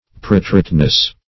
Preteriteness \Pret"er*ite*ness\, n.